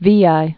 (vēī)